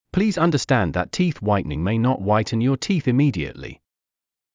ﾌﾟﾘｰｽﾞ ｱﾝﾀﾞｰｽﾀﾝﾄﾞ ｻﾞｯﾄ ﾃｨｰｽ ﾎﾜｲﾄﾆﾝｸﾞ ﾒｲ ﾉｯﾄ ﾎﾜｲﾄﾝ ﾕｱ ﾃｨｰｽ ｲﾐﾃﾞｨｴｲﾄﾘｰ